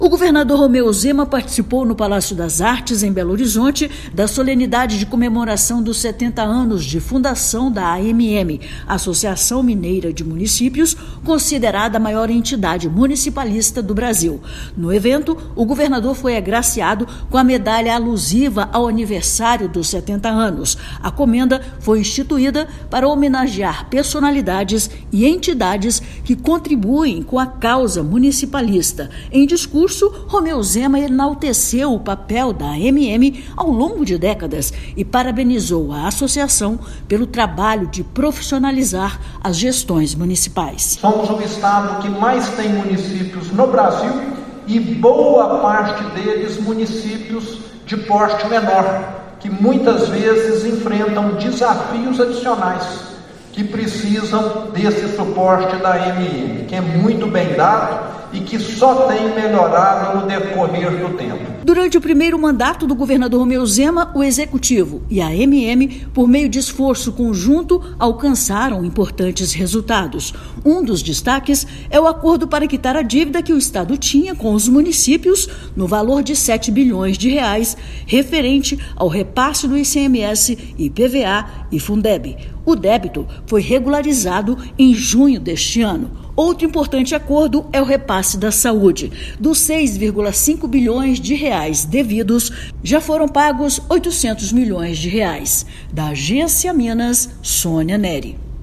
O governador Romeu Zema participou das comemorações e foi agraciado com medalha que homenageia quem contribui com a causa municipalista. Ouça matéria de rádio.